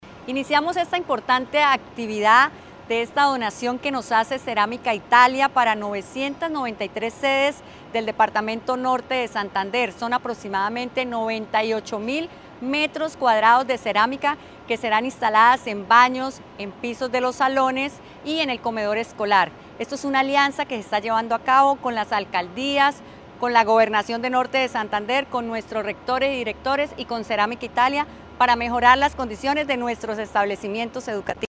1. Audio de Laura Cáceres, secretaria de Educación